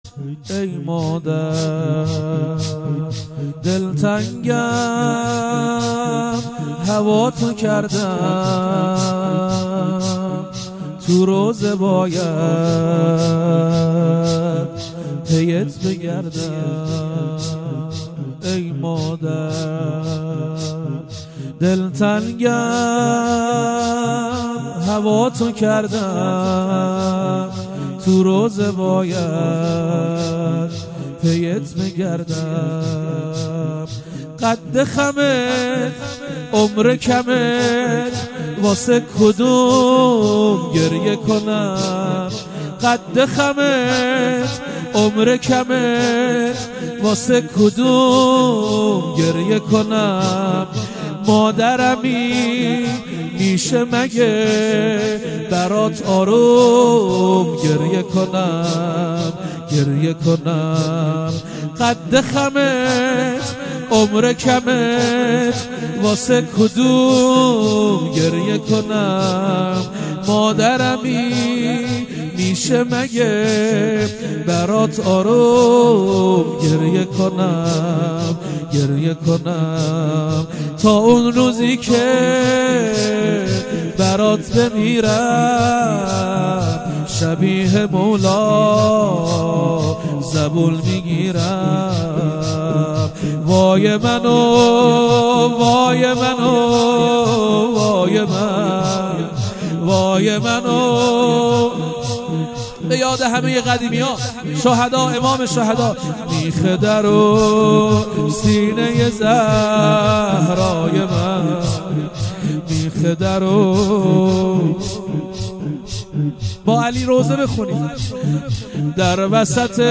مداحی زمینه ای
ایام فاطمیه اول سال1441#مراسم_شب_دوم